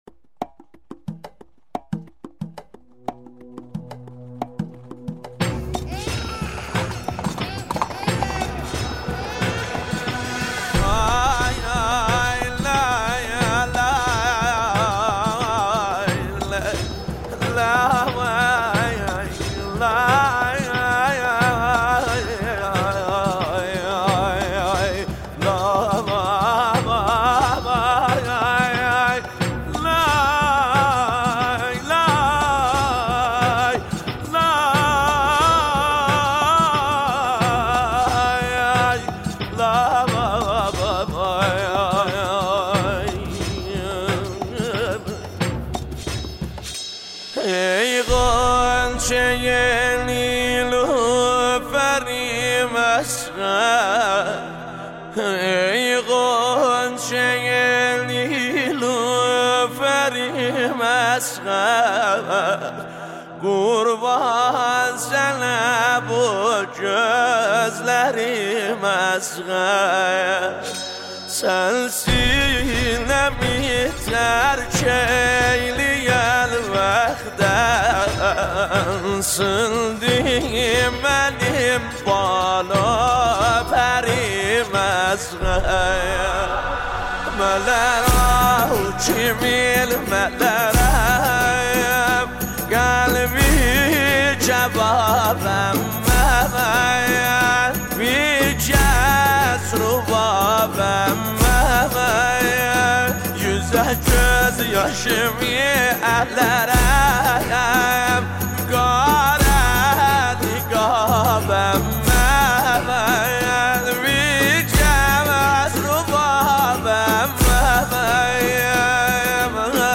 پخش آنلاین نوحه